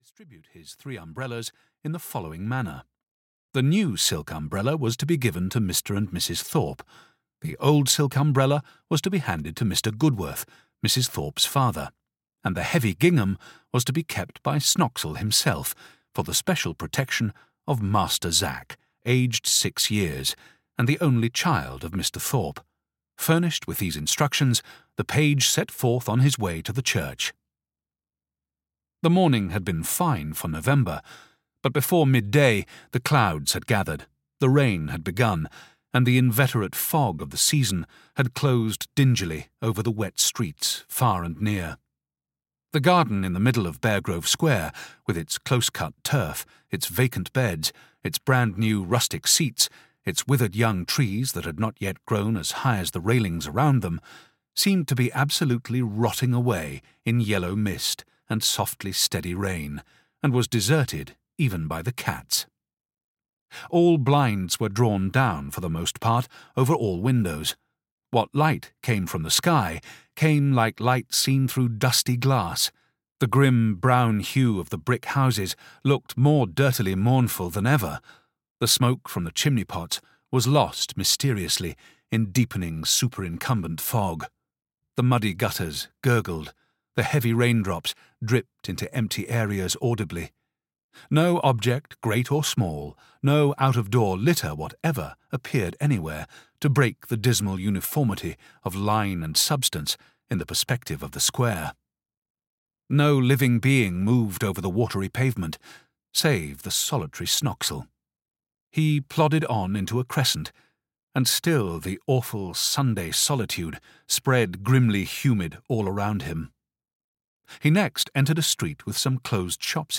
Hide and Seek (EN) audiokniha
Ukázka z knihy